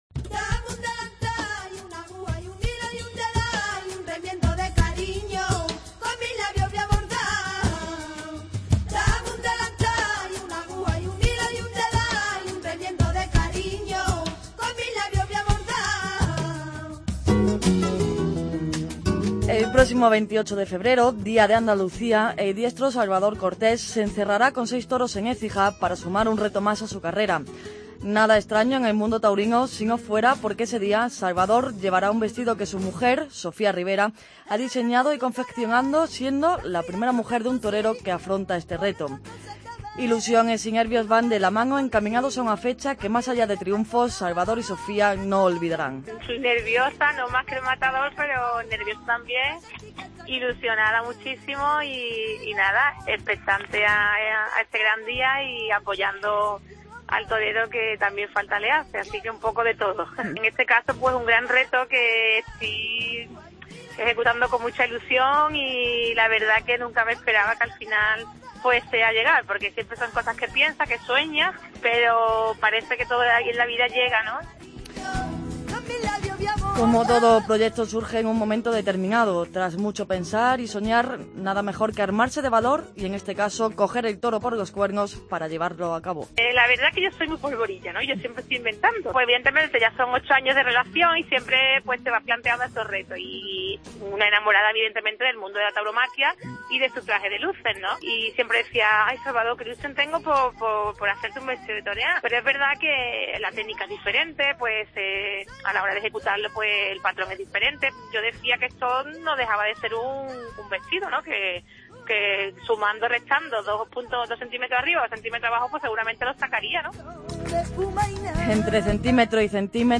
Reportaje